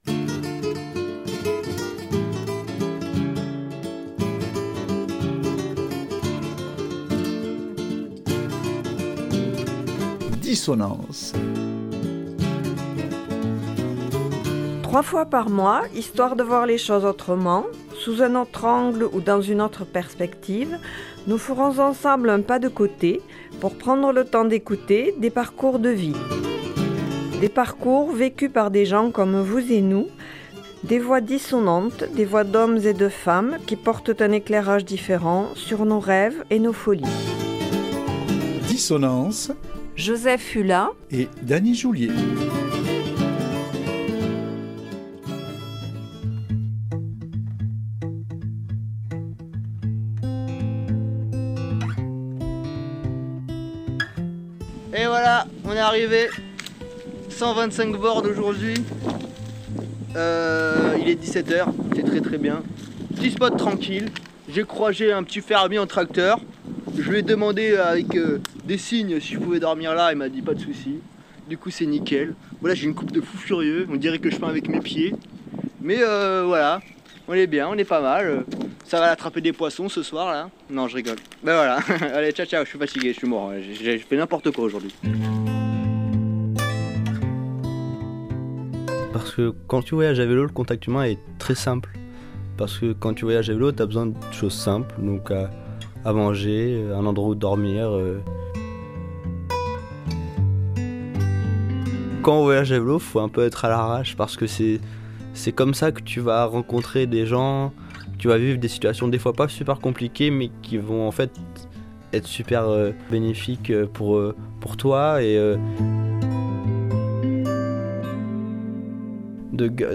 nous donnant à entendre ses enregistrements glanés au cours de son périple en solitaire